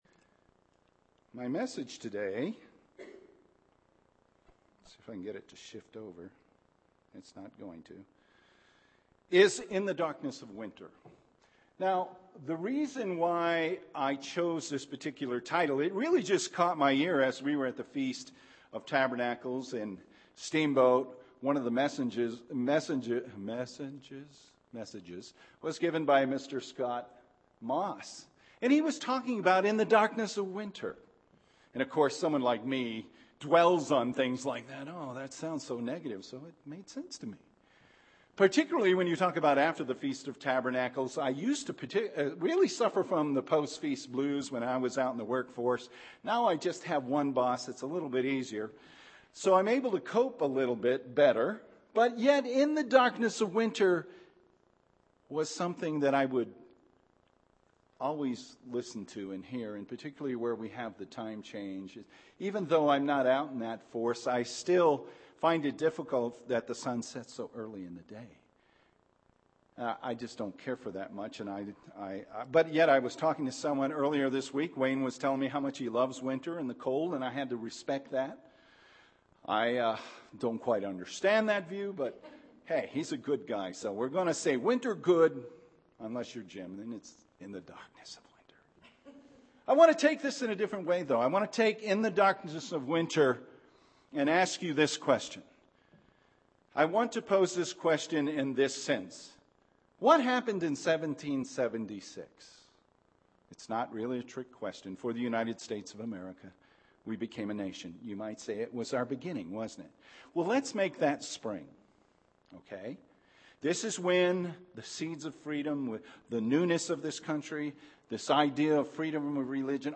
Sermons
Given in Albuquerque, NM